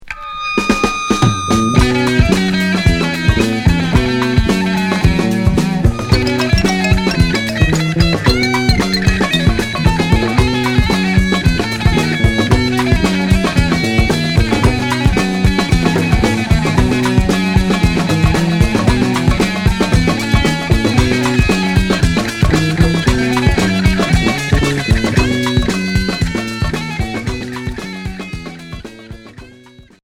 Groove progressif Premier 45t retour à l'accueil